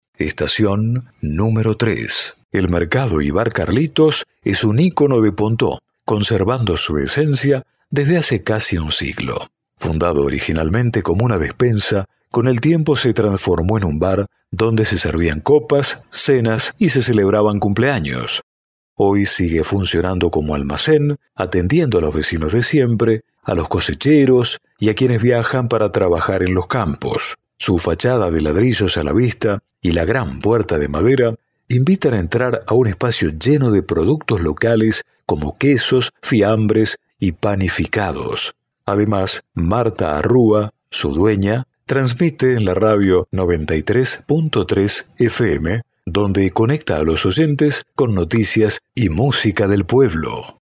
AUDIO-GUIA-PONTEAU-ESTACION-TRES.mp3